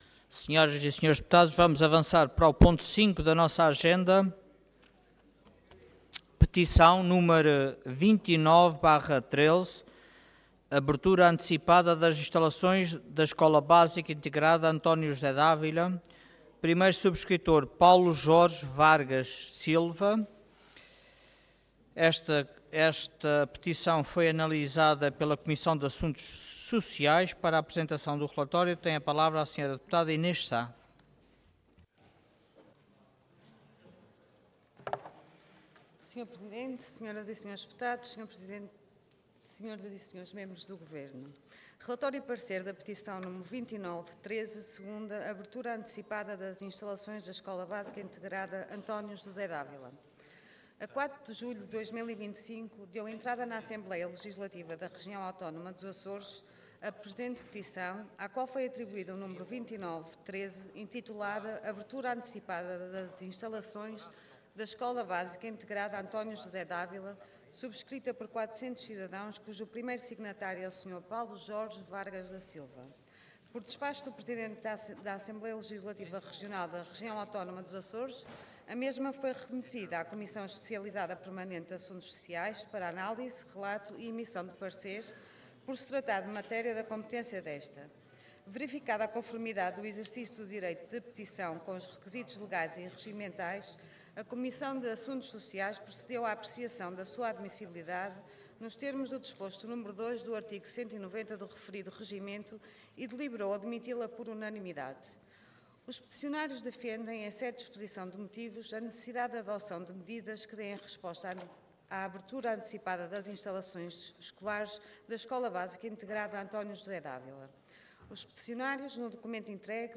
Detalhe de vídeo 14 de outubro de 2025 Download áudio Download vídeo Processo XIII Legislatura Abertura antecipada das instalações da Escola Básica Integrada António José d’Ávila Intervenção Petição Orador Inês Sá Cargo Relatora Entidade Comissão de Assuntos Sociais